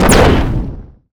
energy_blast_large_03.wav